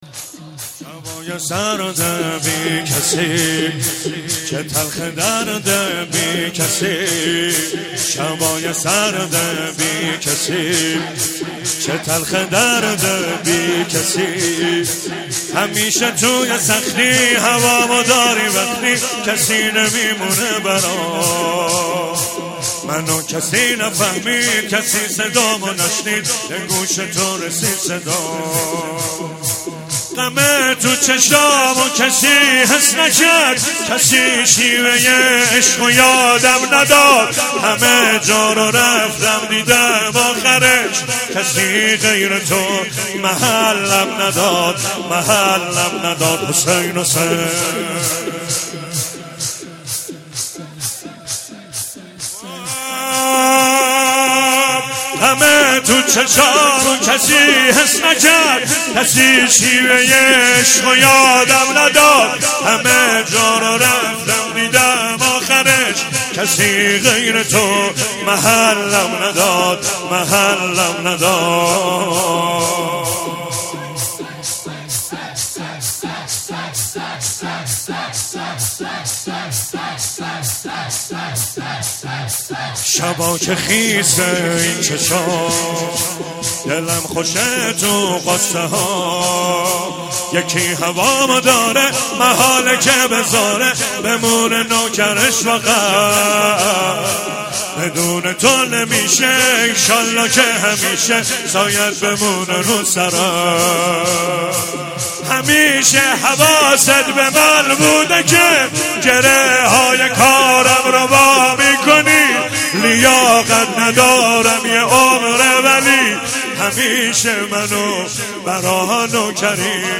مراسم شب اول فاطمیه ۱۳۹7 با مداحی
فاطمیه 1397